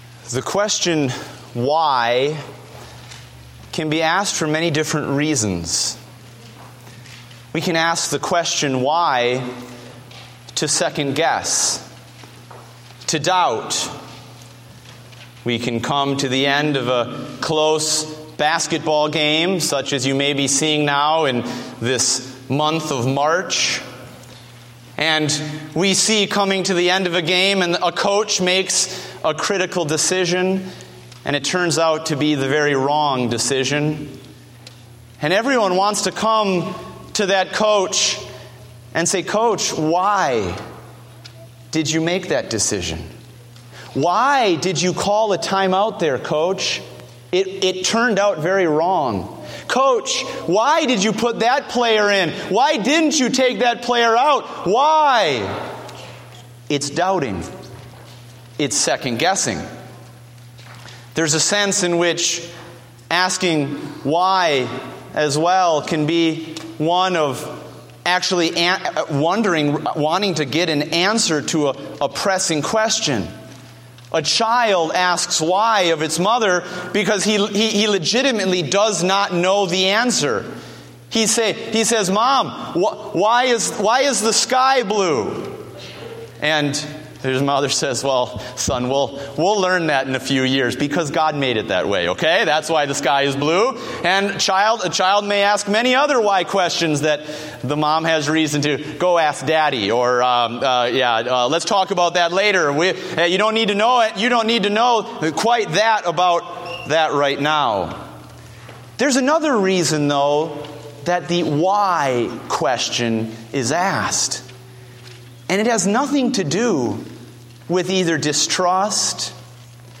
Date: March 22, 2015 (Morning Service)